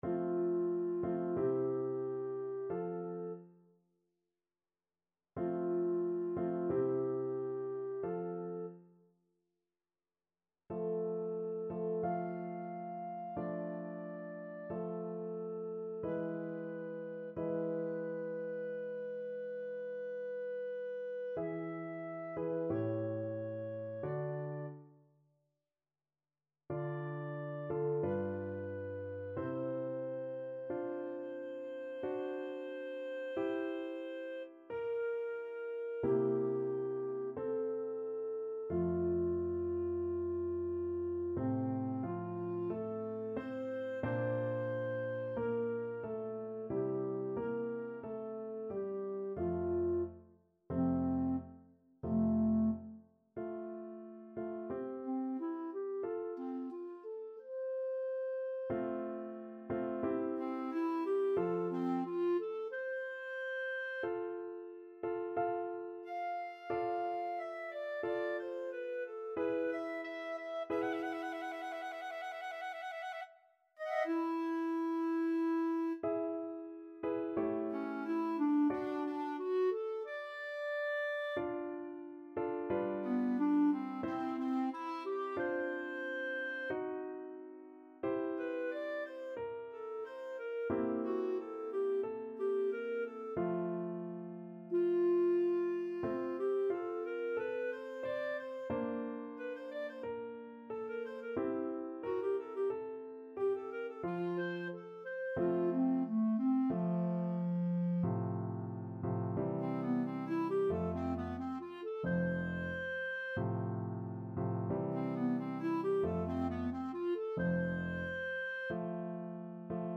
Clarinet version
Larghetto =c.45
4/4 (View more 4/4 Music)
F4-D7
Classical (View more Classical Clarinet Music)